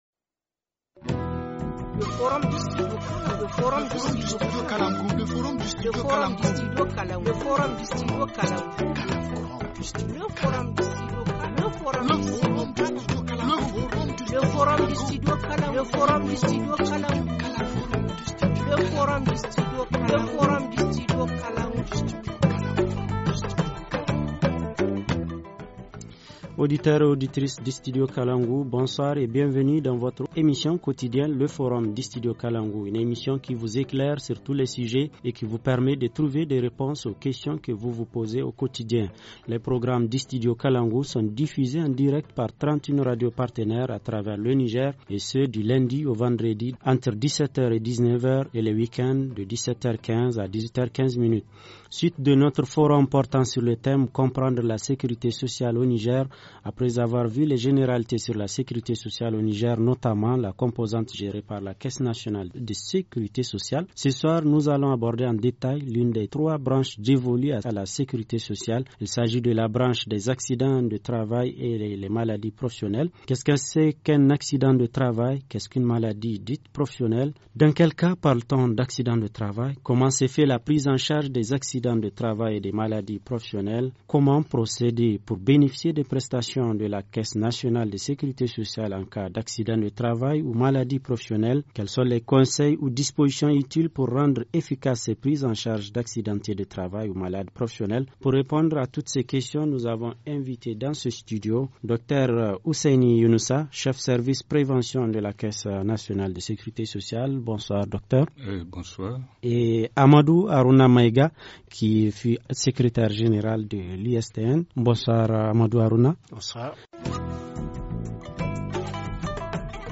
Forum en Français